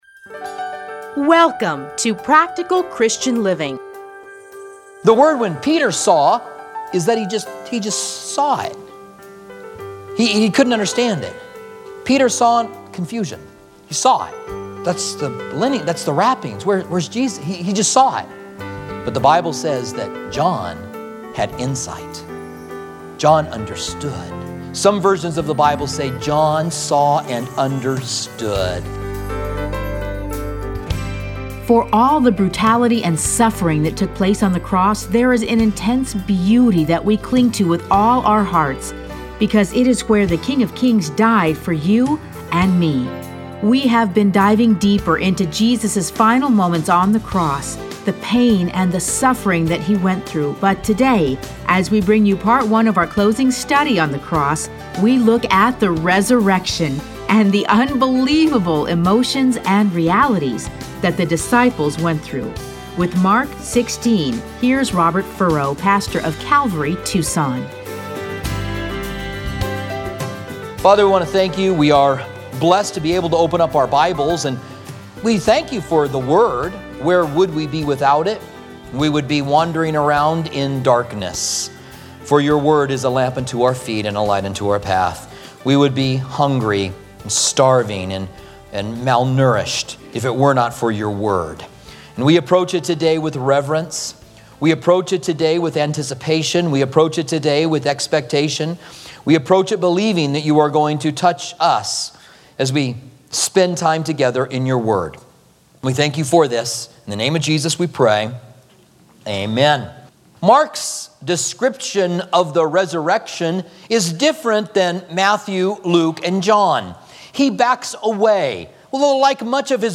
Listen to a teaching from Mark 16.